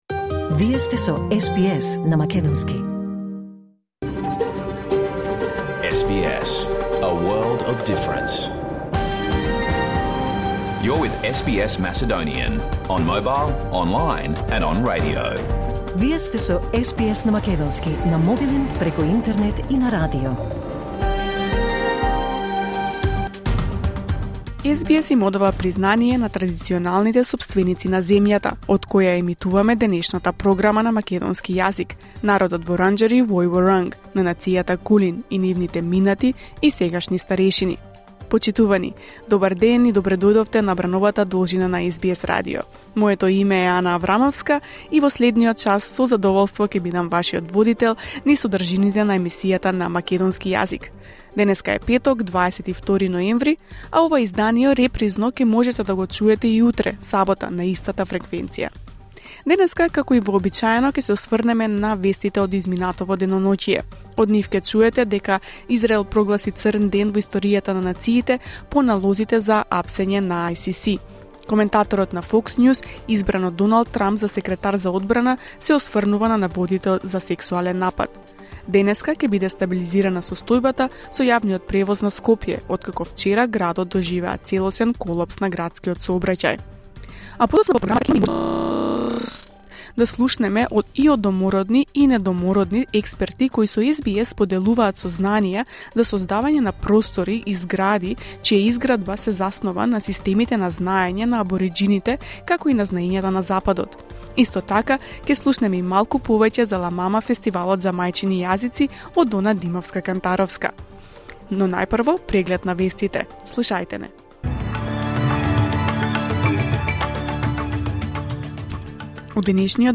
SBS Macedonian Program Live on Air 22 November 2024